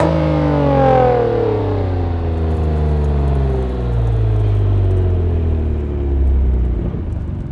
rr3-assets/files/.depot/audio/Vehicles/ttv6_01/ttv6_01_decel.wav
ttv6_01_decel.wav